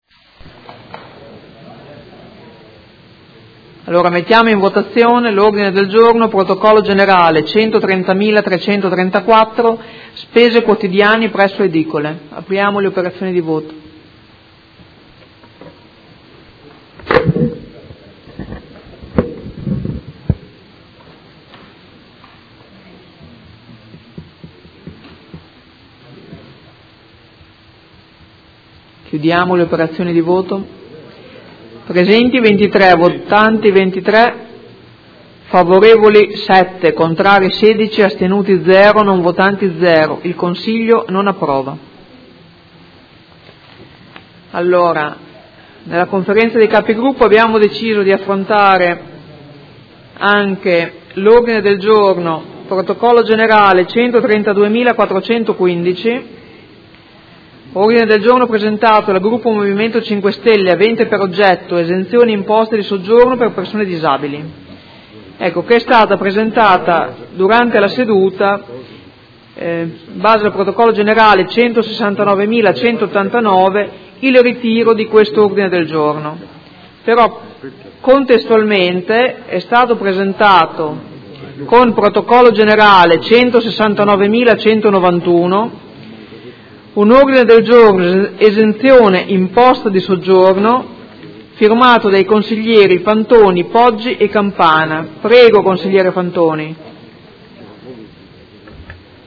Seduta del 17/11/2016. Mette ai voti Mozione presentata dal Gruppo Movimento Cinque Stelle avente per oggetto: Spese quotidiani presso edicole.